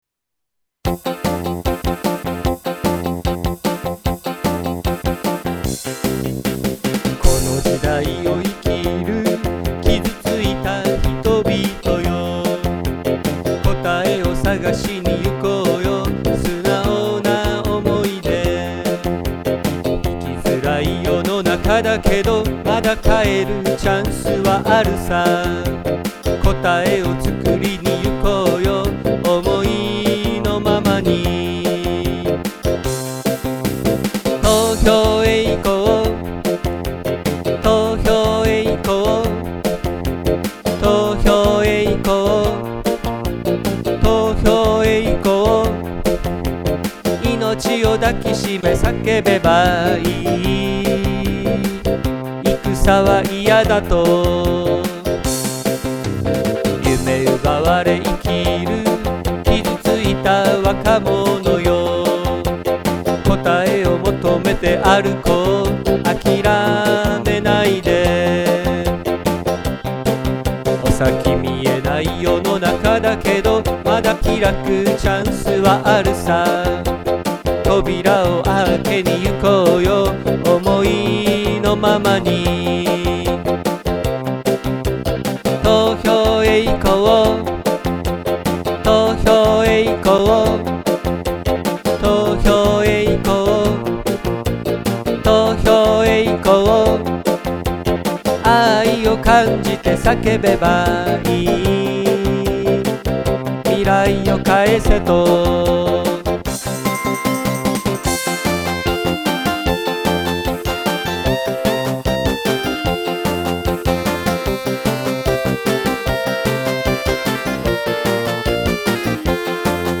叫べ 投票へ行こう～ロックバージョン～
sakebetohyoheyukouVerRock.mp3